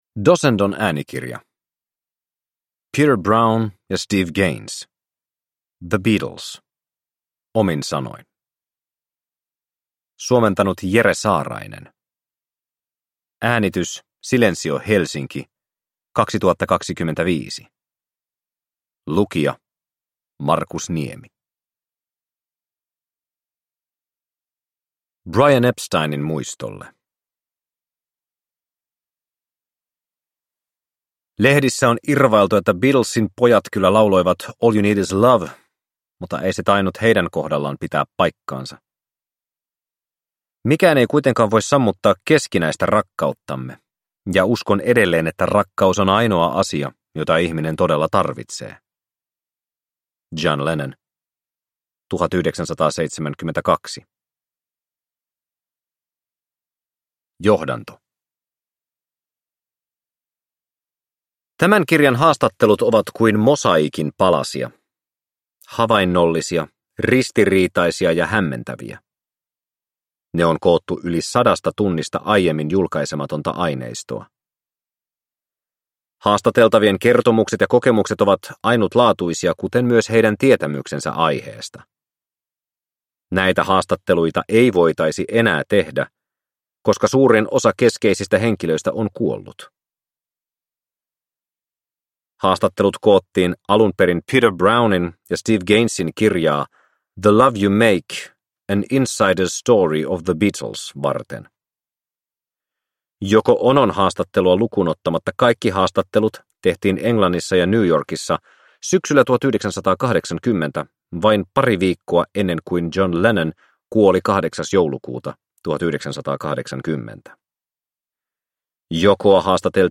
The Beatles – Omin sanoin – Ljudbok